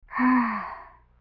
sigh1.wav